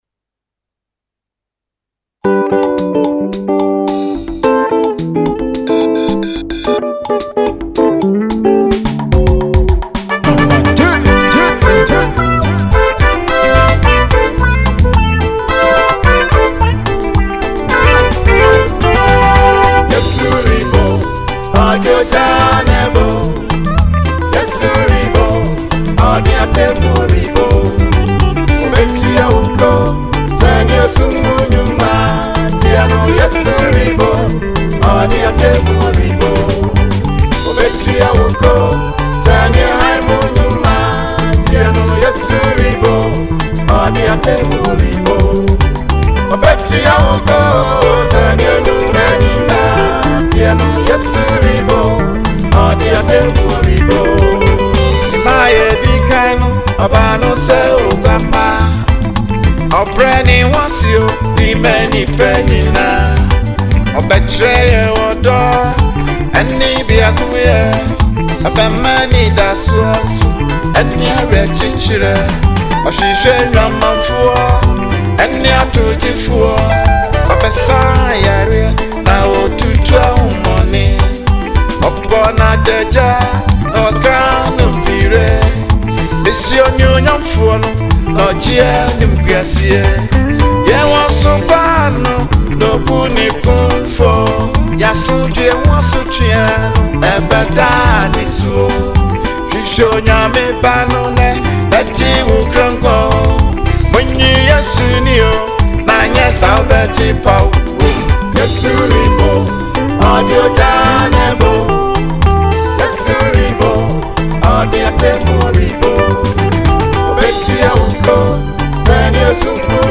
Ghanaian Outstanding gospel artists